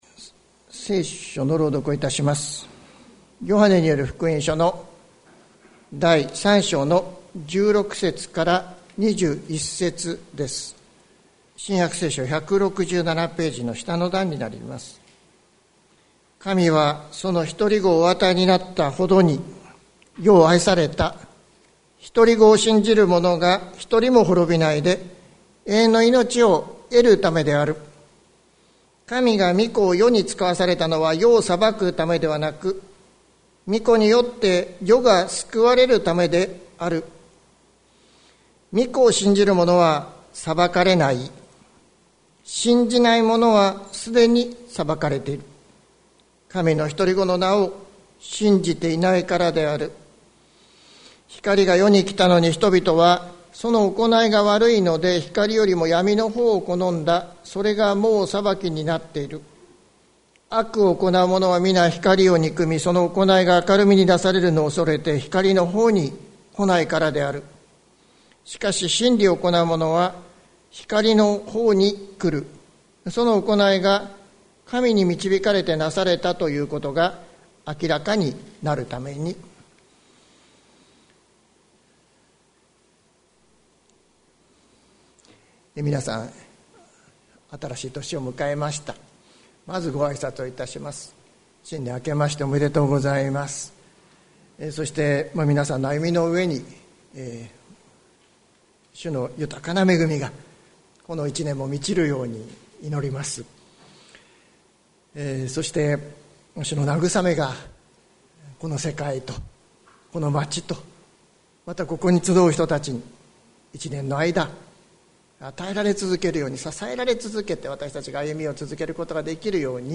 2022年01月02日朝の礼拝「滅びの道と命の道」関キリスト教会
説教アーカイブ。